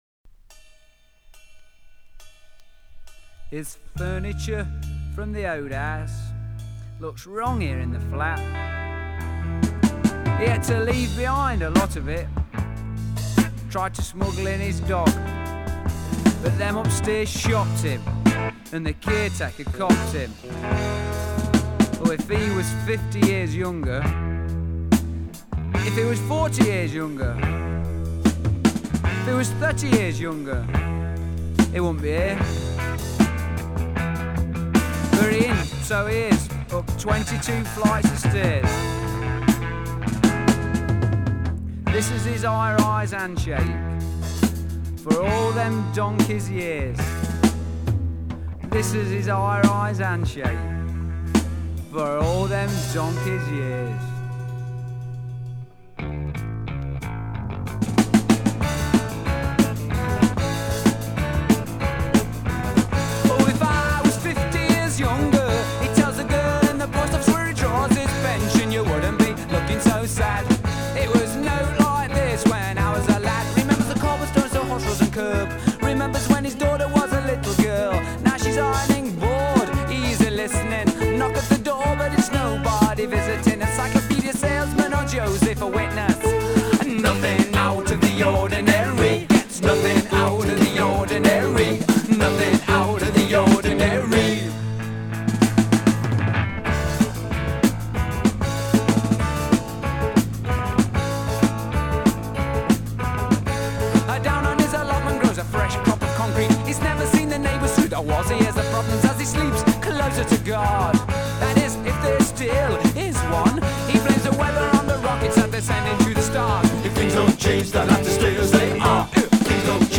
guitar
drums